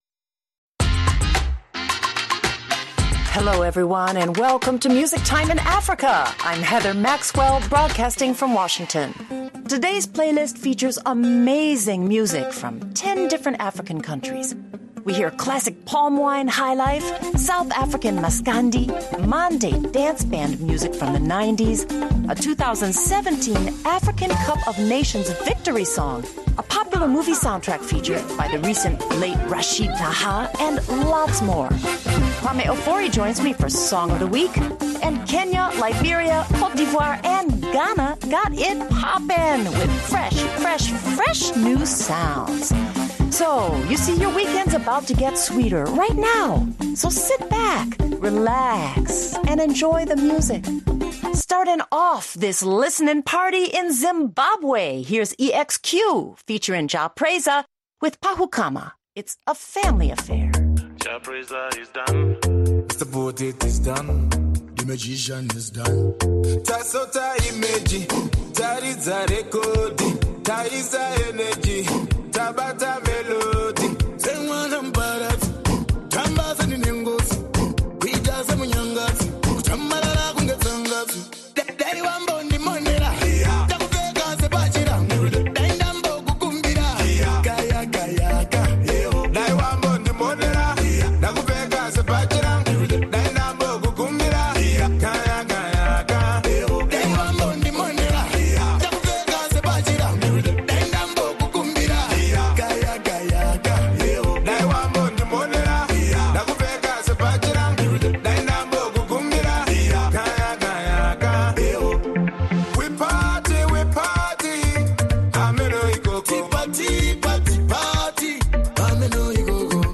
And then it’s back to more African millennial hits that will groove and sway you to the end of the hour.